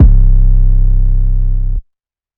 808 (Metro)_Hard -_2.wav